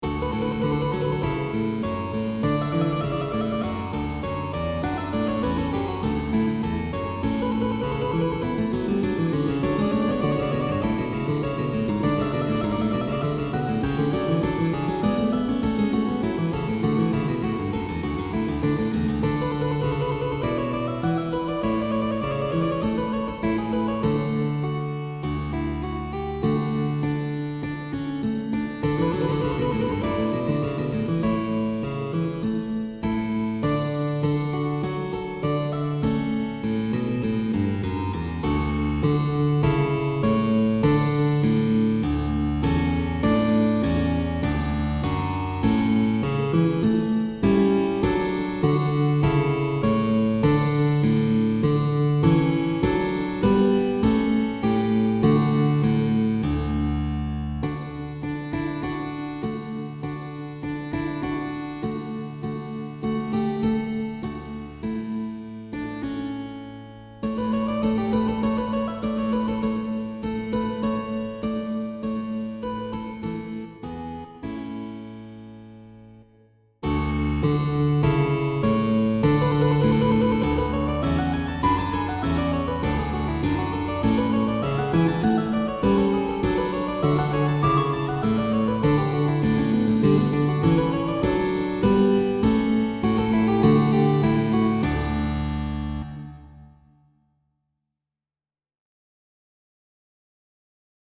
This is the Notion 3 score and MP3 for the ornamented Harpsichord, which is "Harpsichord 2" in Miroslav Philharmonik, and it only has the Harpsichord part . . .
I used notation trills in a few places for half notes, and they work nicely for some of the half notes but probably can be improved for other half notes, which mostly is a matter of using the correct type of notation trill rather than the same type of trill for all the trilled half notes . . .
And I only ornamented some of the Harpsichord phrases, so approximately half of the Harpsichord is ornamented and the other half is as it was originally, which I think works better, since there is a bit of contrast this way rather than everything going rapidly all the time . . .
Ornamented-Harpsichord2-Miroslav-Philharmonik.mp3